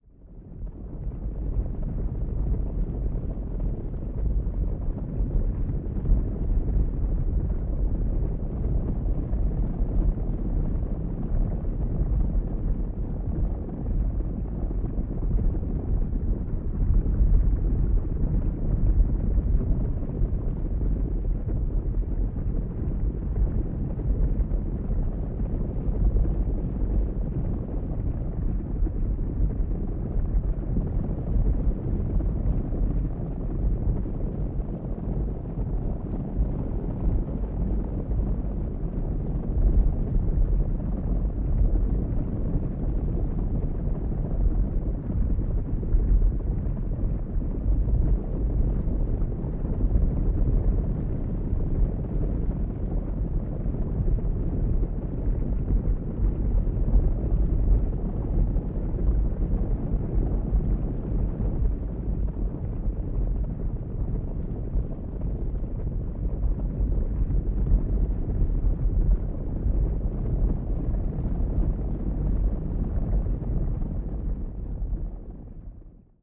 Громкие, ритмичные удары копыт создают эффект присутствия – используйте для звукового оформления, релаксации или творческих проектов.
Гул приближающегося табуна лошадей